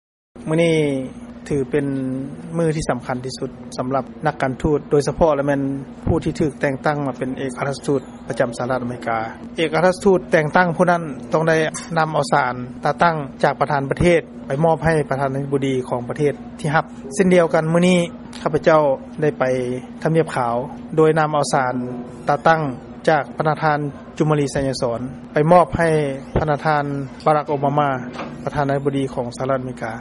ຟັງຄຳຖະແຫລງຕອນນຶ່ງ ຂອງ ພະນະທ່ານ ເອກອັກຄະລັດຖະທູດ ໄມ ໄຊຍະວົງ ປະຈຳນະຄອນຫລວງ ວໍຊິງຕັນ